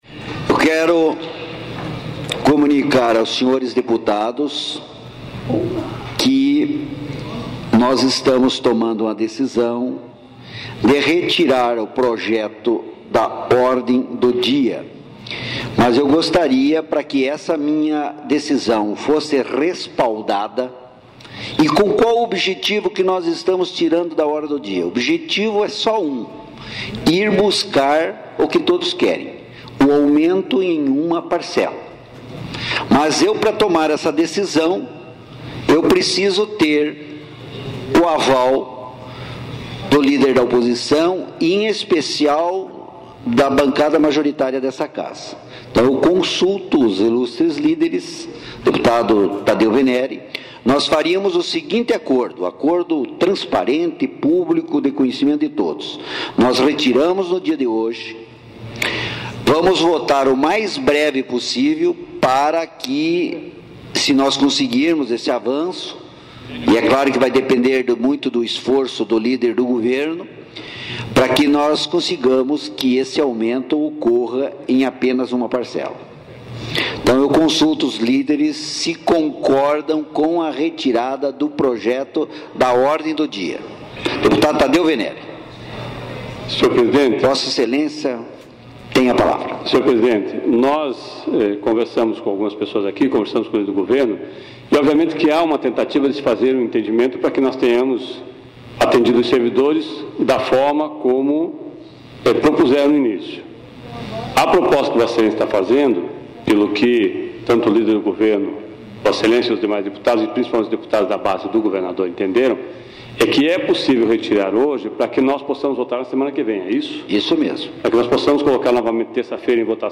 O presidente da Assembleia Legislativa, deputado Valdir Rossoni, anunciou durante sessão extraordinária nesta terça-feira que a votação do projeto que estabelece reajuste anual de 6,49% sobre as tabelas básicas dos salários dos servidores estaduais foi adiada.//
Confira o momento do anúncio, com as falas dos deputados Tadeu Veneri, do PT, líder da oposição; e Ademar Traiano, do PSDB, líder do governo.//